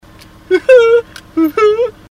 Laugh 39